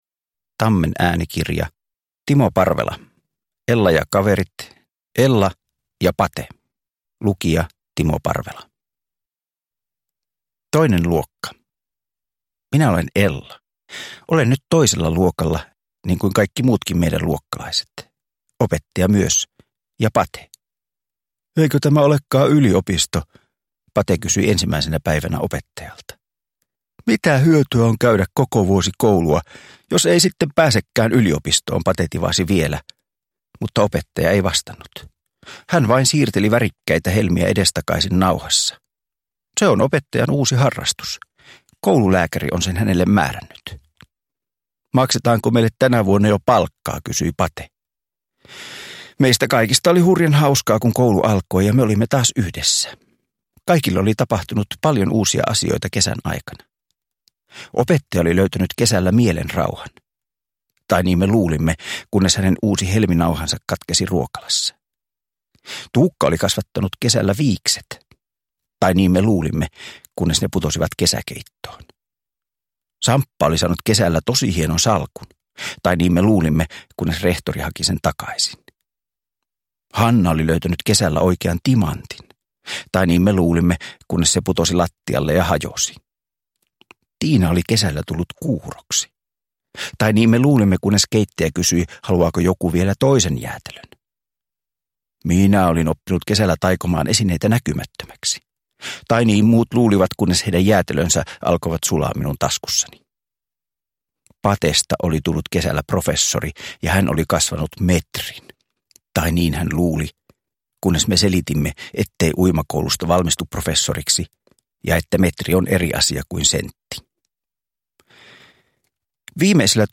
Ella ja Pate – Ljudbok
Uppläsare: Timo Parvela